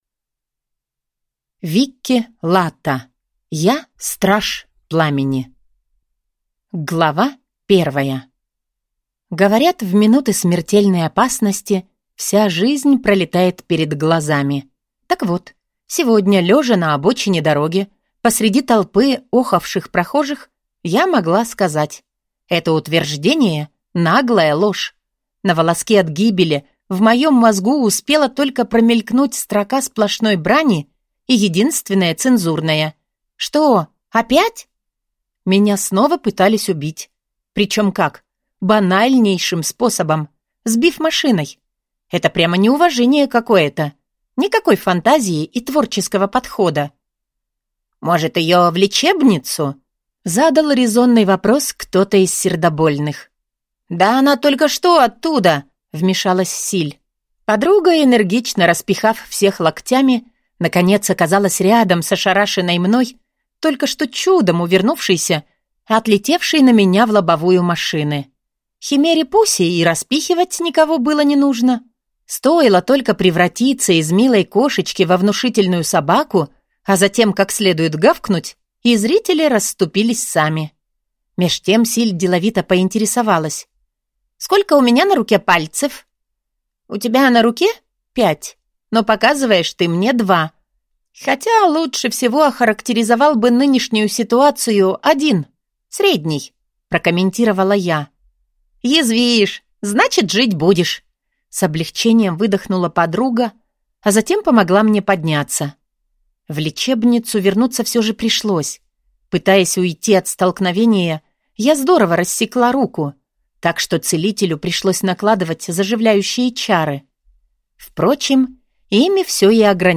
Аудиокнига Я – страж пламени!